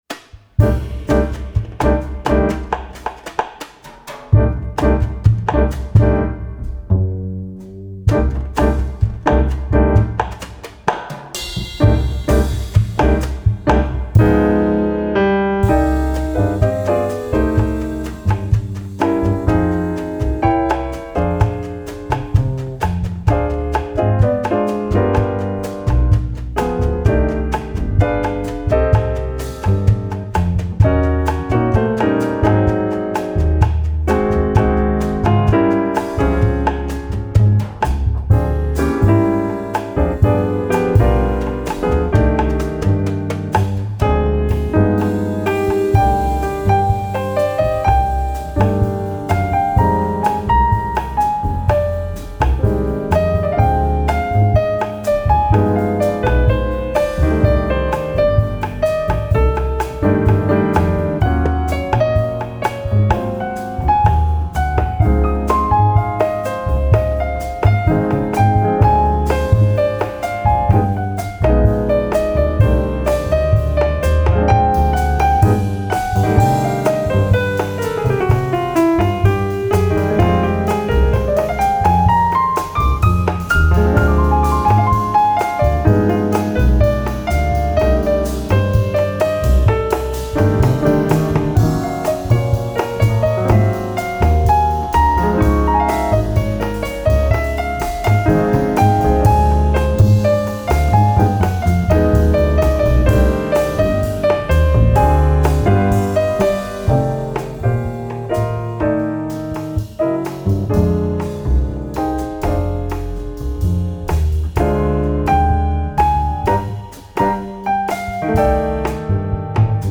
erweiterte Version mit Bass Solo
Piano
Kontrabass
Drums